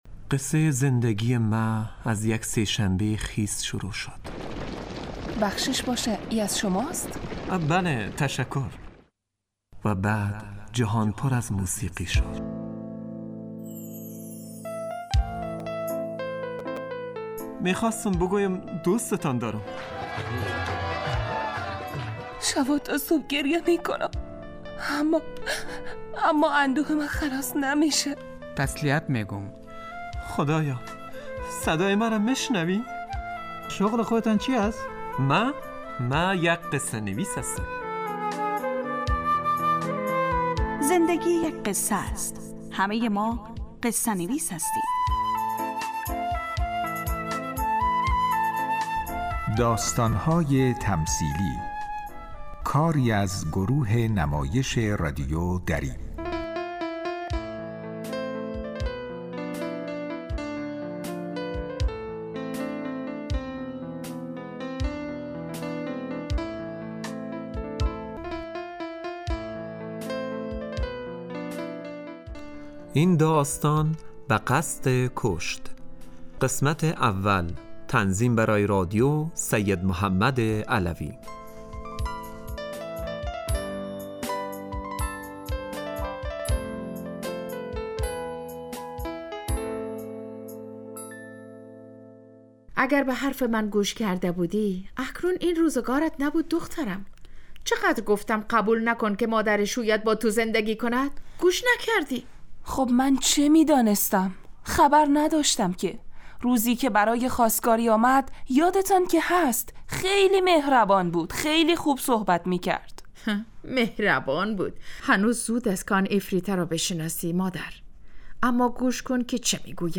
داستانهای تمثیلی نمایش 15 دقیقه ای هست که از شنبه تا پنج شنبه ساعت 03:20 عصربه وقت افغانستان پخش می شود.